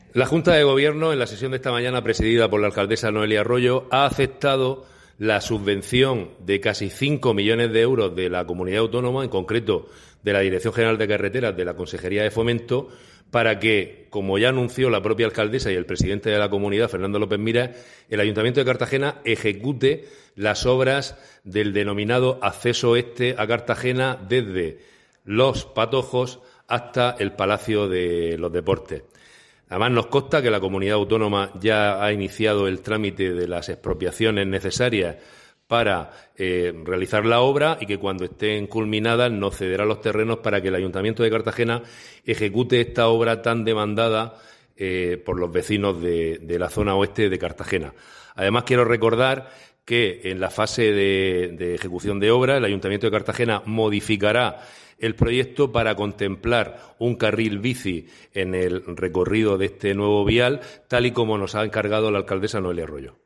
Enlace a Declaraciones del concejal Diego Ortega sobre el acceso oeste a Cartagena